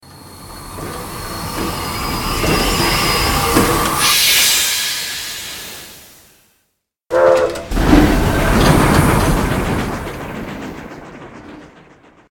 CBHQ_TRAIN_stopstart.ogg